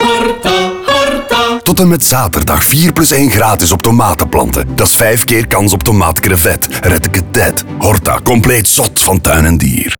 Vier radiospots die opnieuw de zintuigen prikkelen.